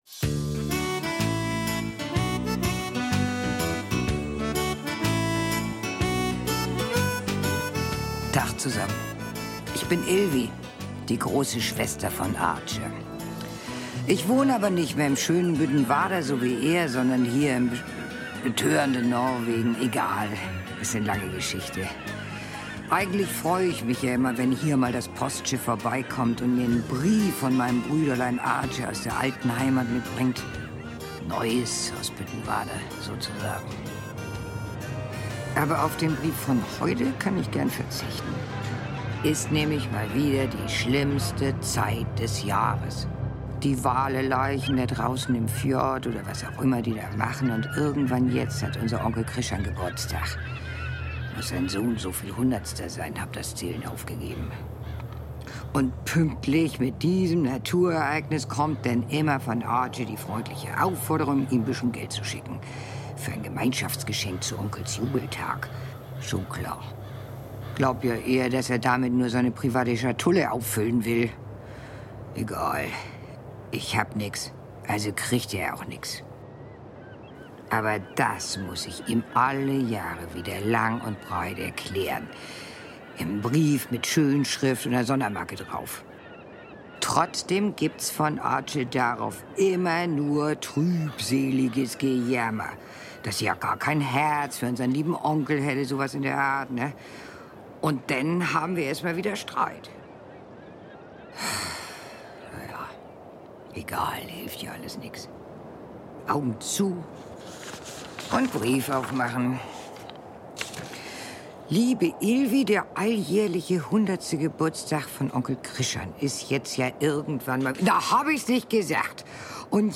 Büttenwarder-Hörspiel: Schafwölkchen ~ Neues aus Büttenwarder Podcast